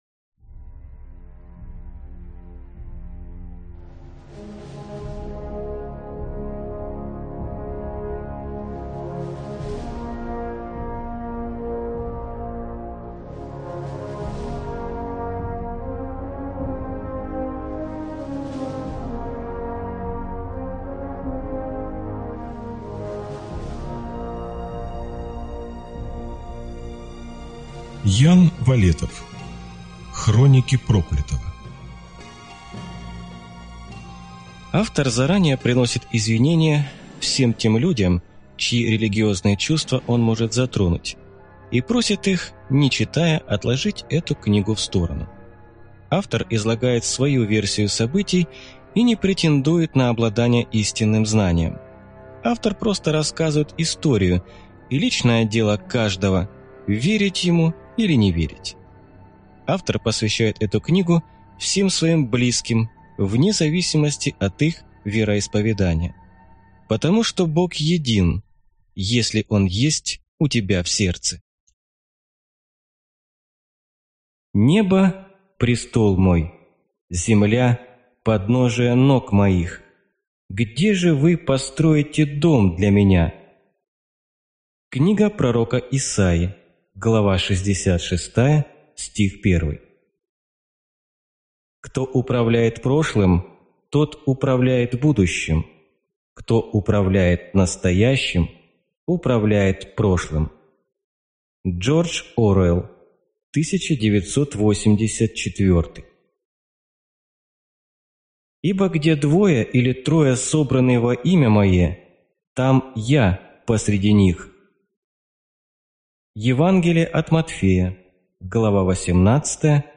Аудиокнига Хроники проклятого | Библиотека аудиокниг